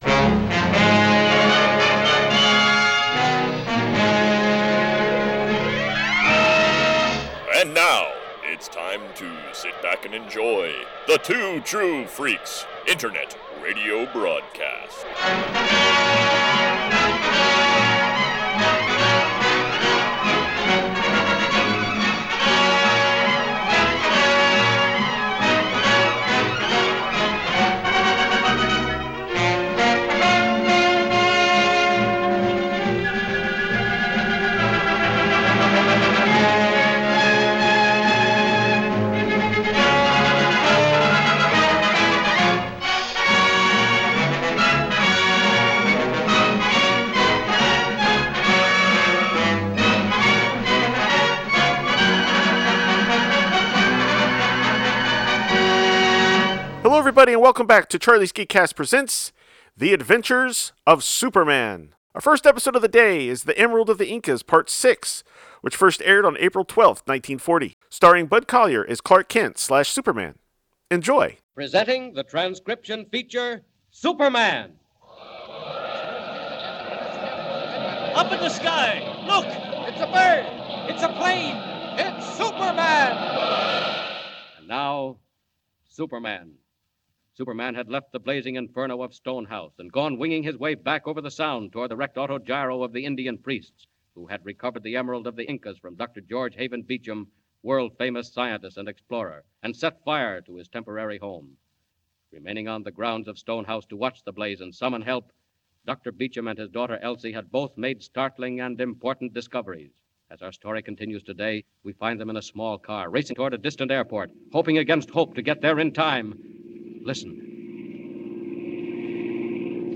See author's posts Tagged as: radio series , Bud Collyer , Metropolis , clark kent , Daily Plant , Superman , Lois Lane , Perry White , Krypton . email Rate it 1 2 3 4 5